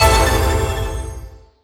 confirm-selection.wav